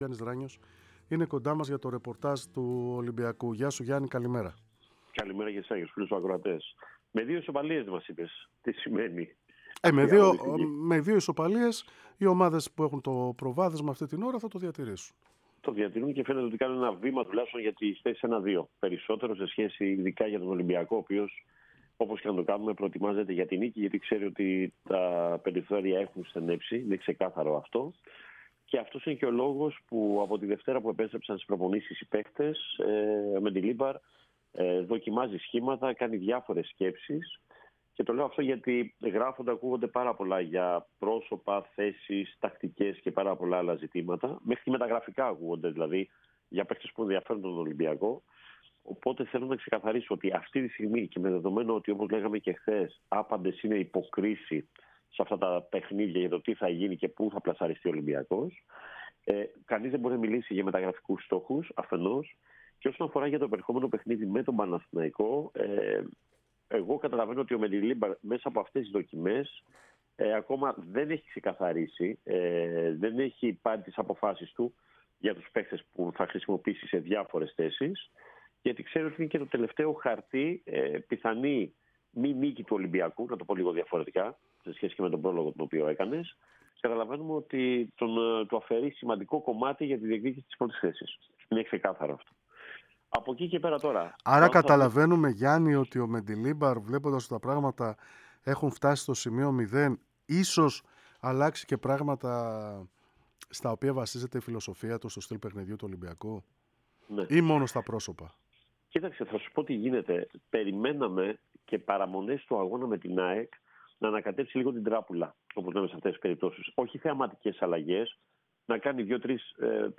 μίλησε στον αέρα της ΕΡΑ ΣΠΟΡ και στην εκπομπή "3-5-2"